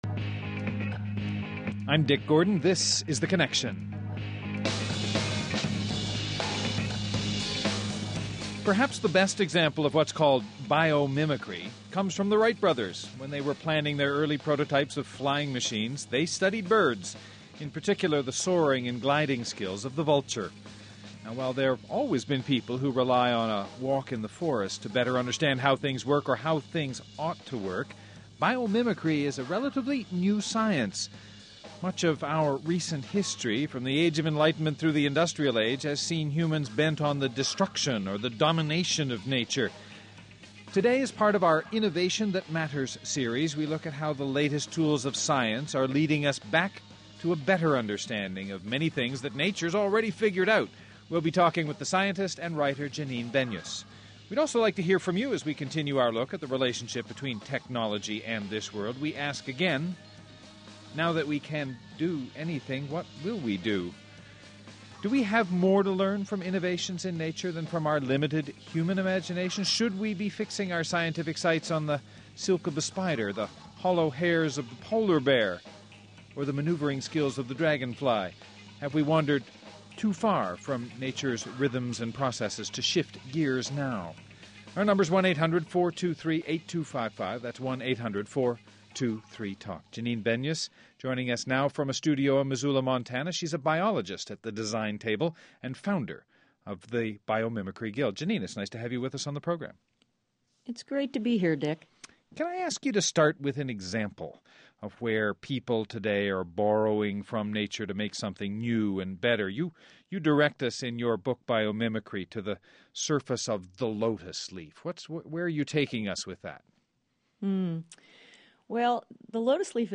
In this hour scientist and writer, Janine Benyus talks about finding her answers during a walk in the woods.